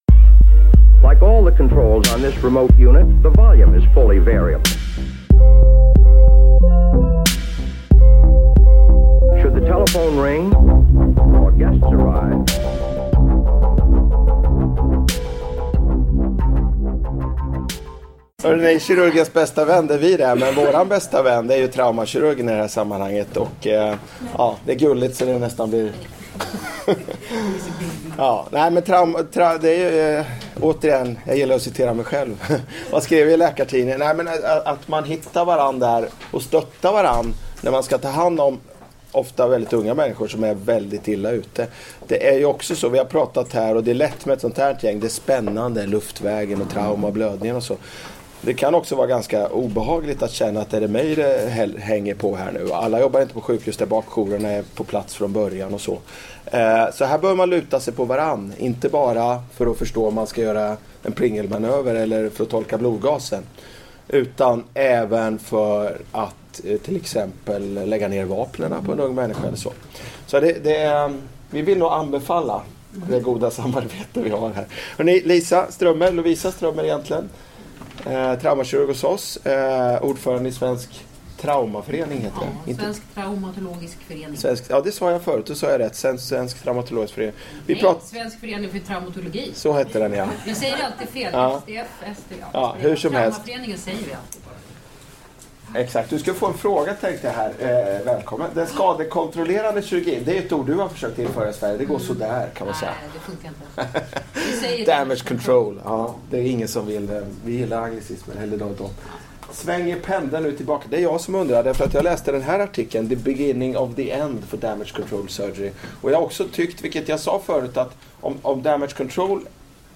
Föreläsningen hölls under traumAAnestesi kursen 2016.